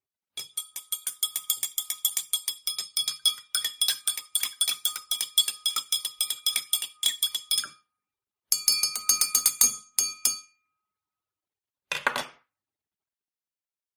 Tiếng Khuấy nước trong Ly
Thể loại: Tiếng ăn uống
Description: Âm thanh “leng keng” trong trẻo, vang vọng nhẹ như tiếng kim loại chạm vào thuỷ tinh, đôi khi xen chút “lách cách” hay “ting ting” nhịp nhàng.
tieng-khuay-nuoc-trong-ly-www_tiengdong_com.mp3